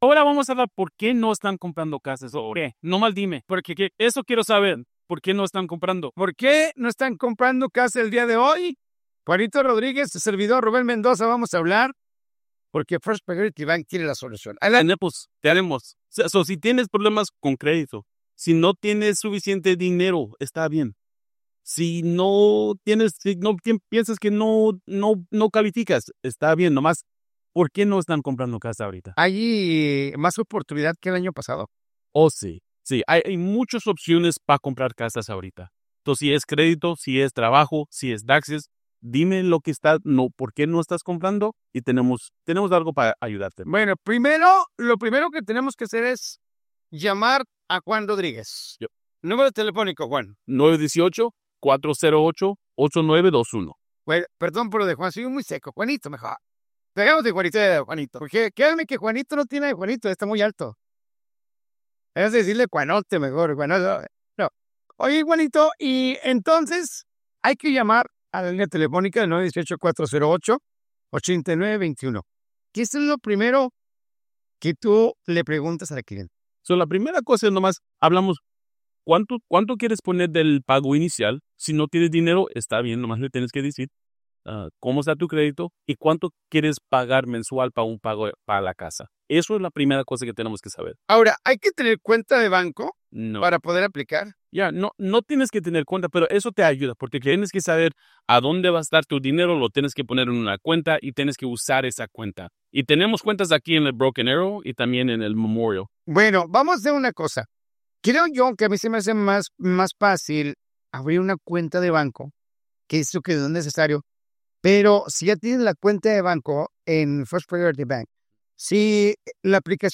Entrevista-FirstPryorityBank-20Enero26.mp3